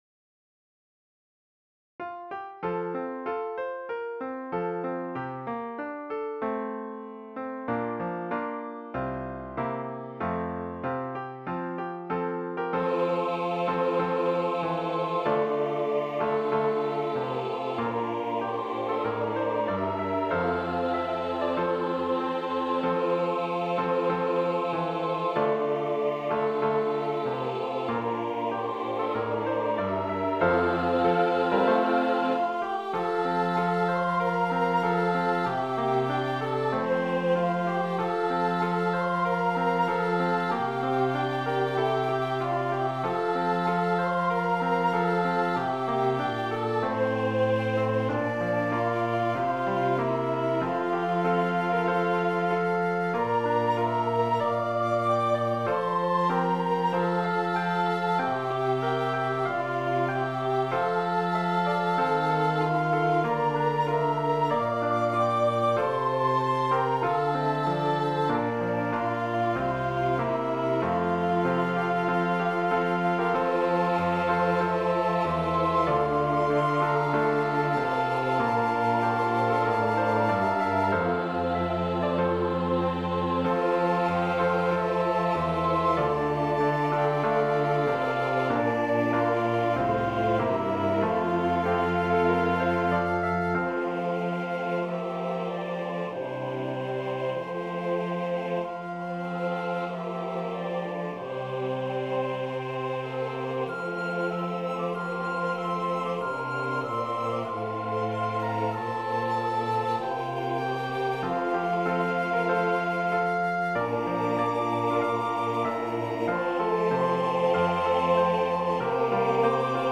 An original composition for Christmas (SATB with children’s chorus, piano, cello, 2 flutes)
Here is a computer generated mp3 recording.